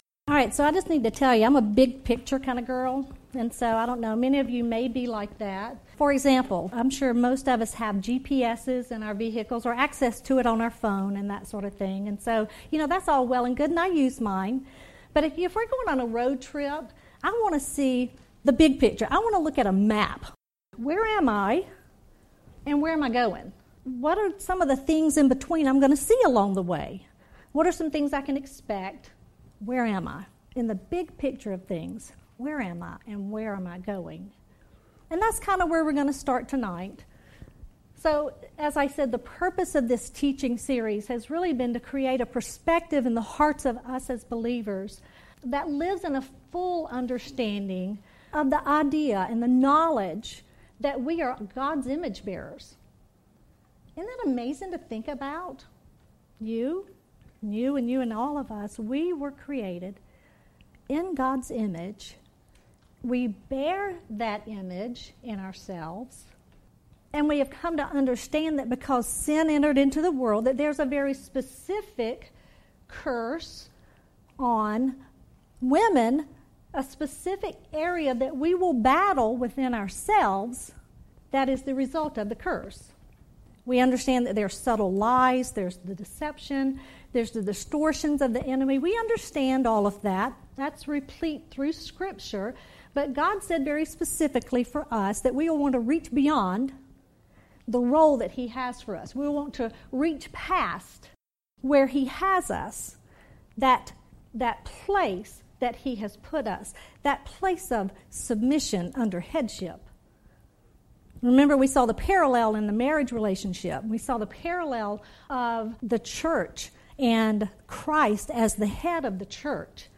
Audio Sessions of Ladies Conferences at Westside Baptist Church